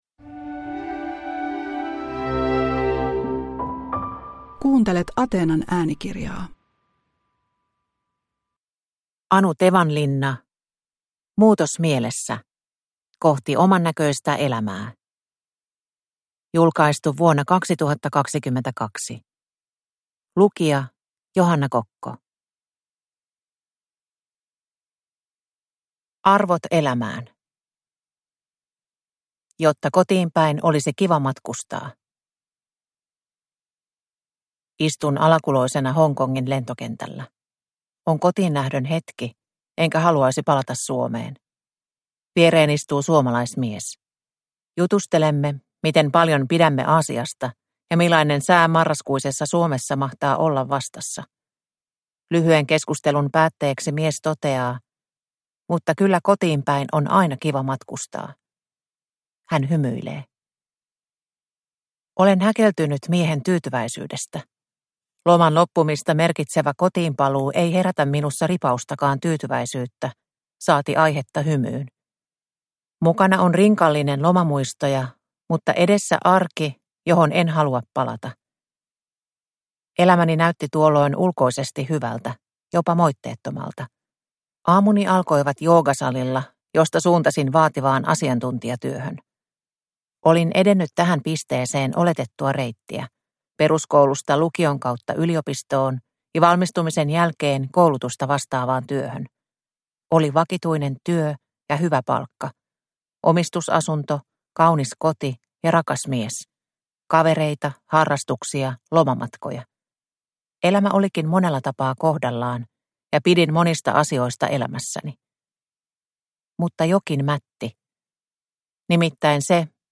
Muutos mielessä – Ljudbok – Laddas ner